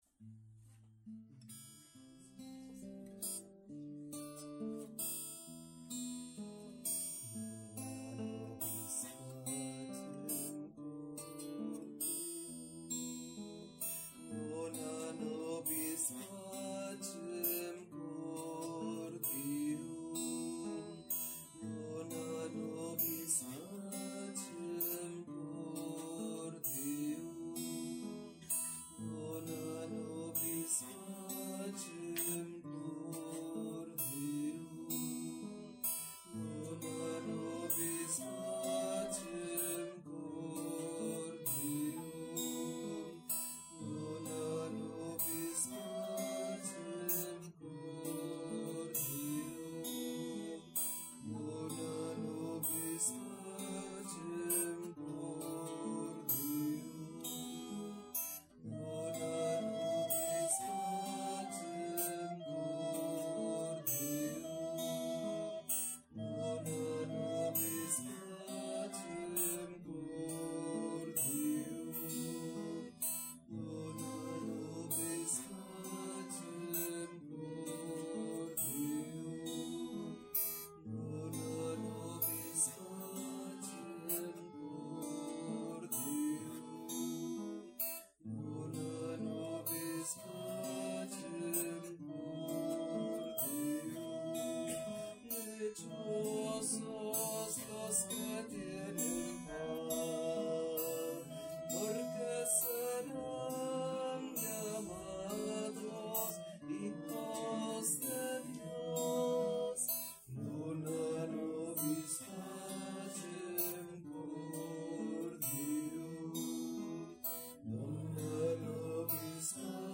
Pregària de Taizé a Mataró... des de febrer de 2001
Parròquia M.D. de Montserrat - Diumenge 26 de febrer de 2023
Vàrem cantar...